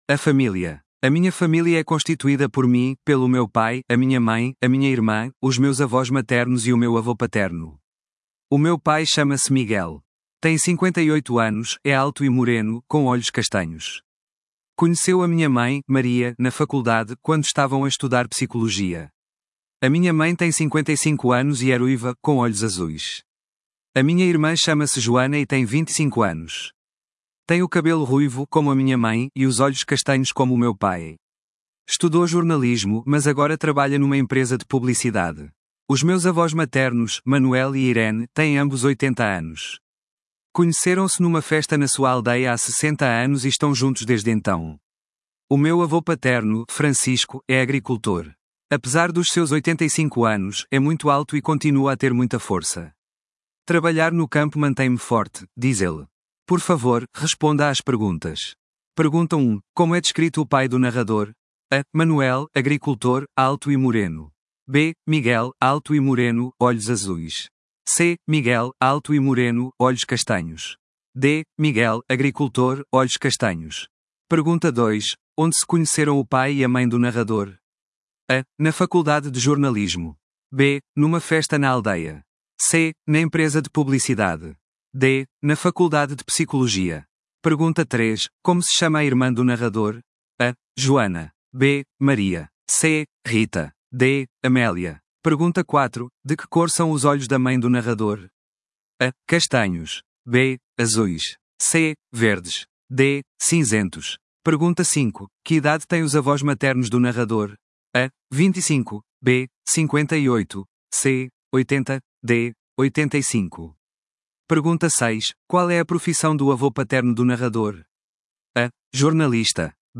Portugal